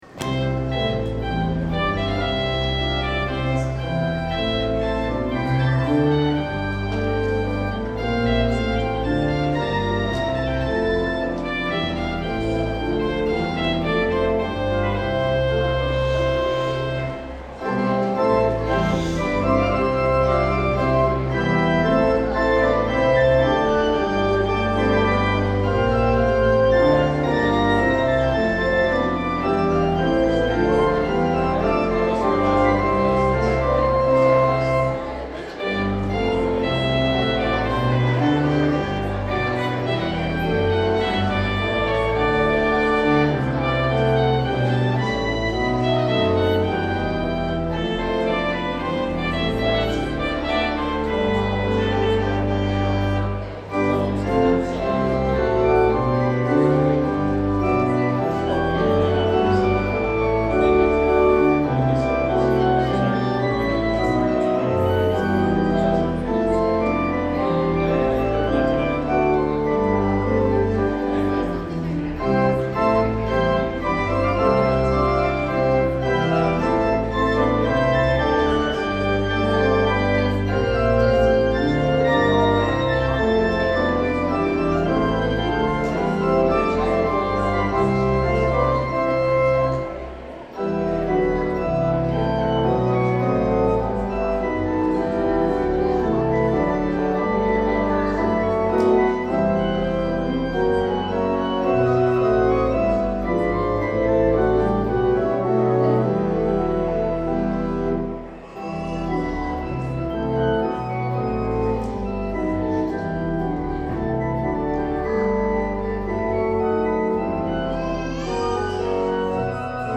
Postlude 2017-02-05
Organist
Organ Solo